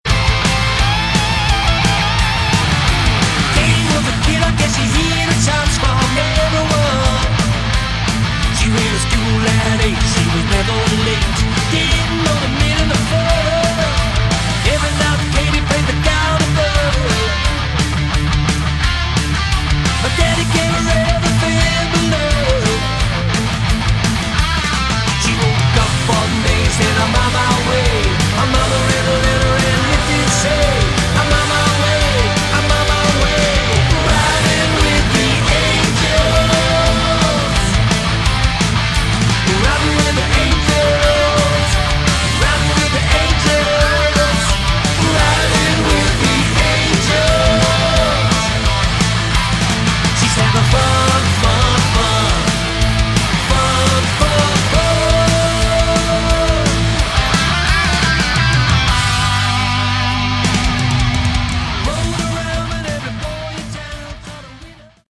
Category: Melodic Metal
vocals
bass